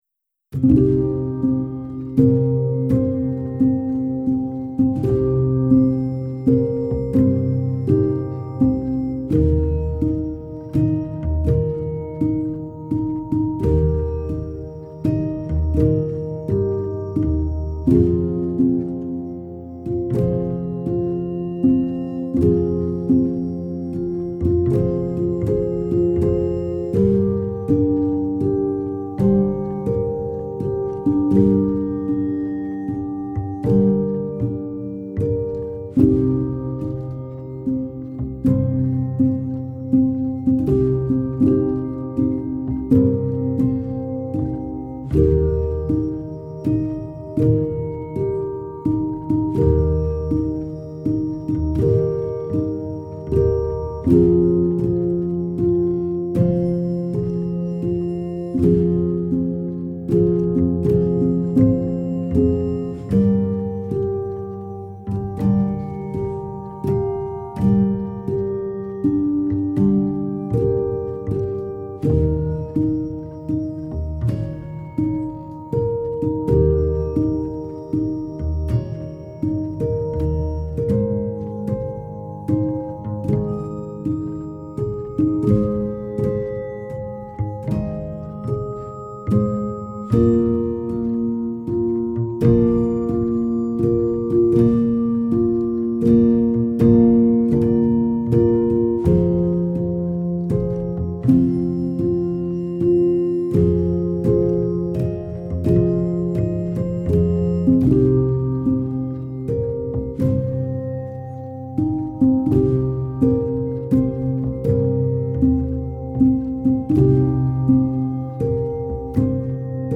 Minimal